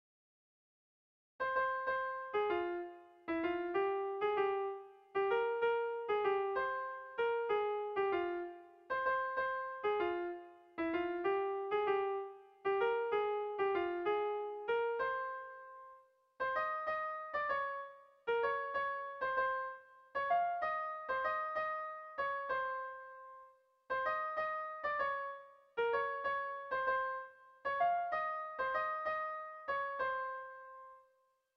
Zortziko handia (hg) / Lau puntuko handia (ip)
A1A2B1B2